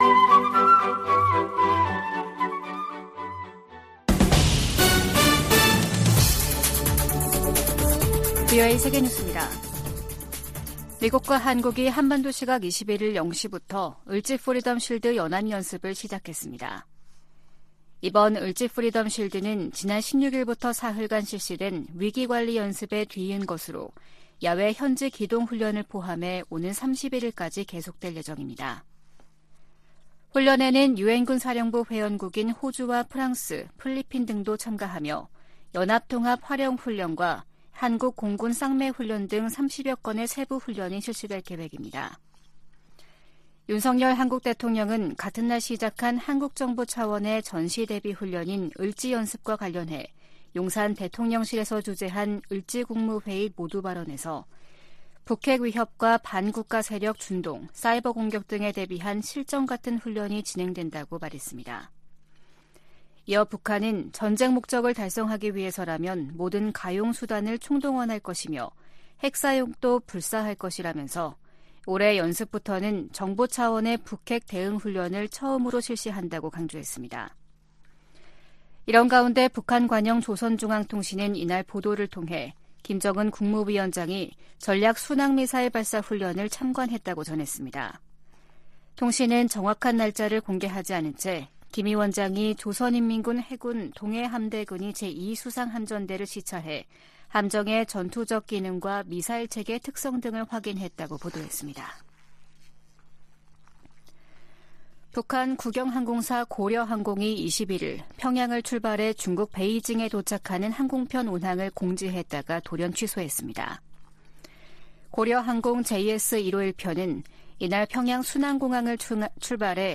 VOA 한국어 아침 뉴스 프로그램 '워싱턴 뉴스 광장' 2023년 8월 22일 방송입니다. 미국과 한국, 일본 정상들은 18일 채택한 캠프 데이비드 정신에서 3국 정상 회의를 연 1회 이상 개최하기로 합의했습니다.